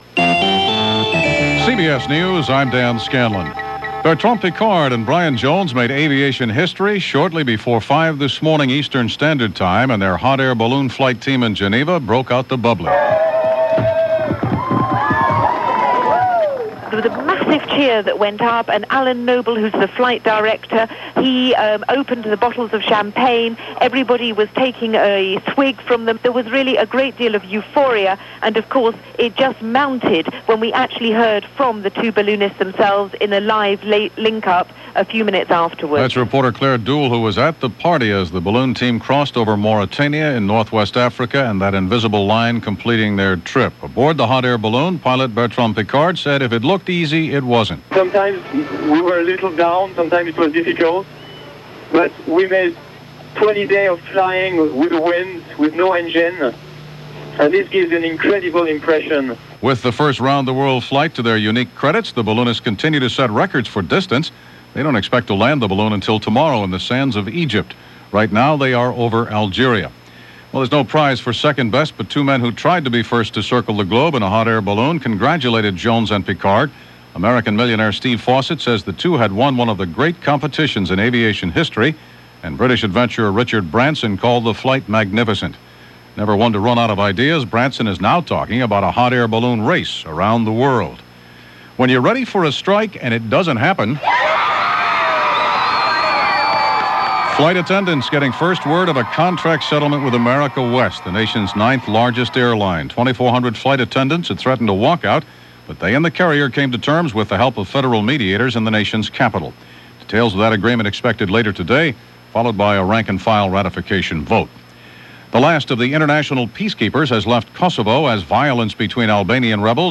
And, as was the custom on Saturdays (as March 20th fell on a Saturday in 1999), President Clinton delivered his weekly radio address. The subject was gun violence and a summit was taking place in the White House to tackle the problem.